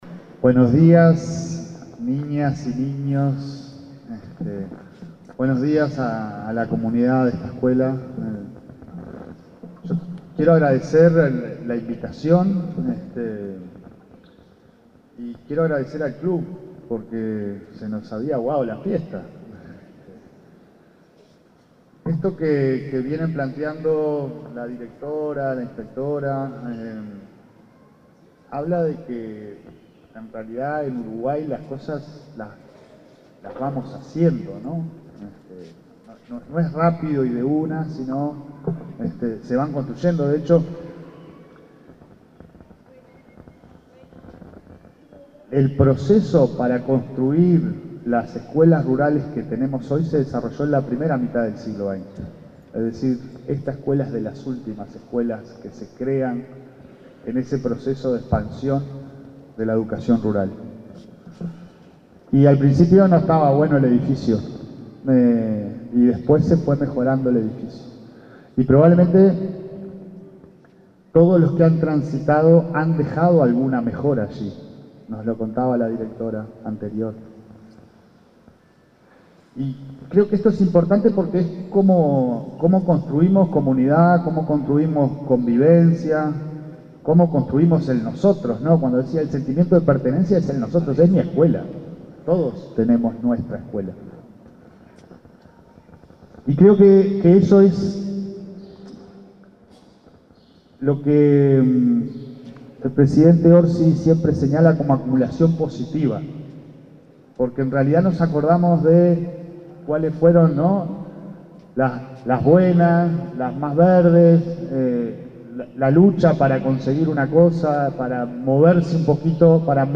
Palabras del presidente de ANEP, Pablo Caggiani
Palabras del presidente de ANEP, Pablo Caggiani 01/08/2025 Compartir Facebook X Copiar enlace WhatsApp LinkedIn El presidente de la Administración Nacional de Educación Pública (ANEP), Pablo Caggiani, se expresó en el 75.° aniversario de la escuela n.° 113 de Nueva Palmira, Colonia.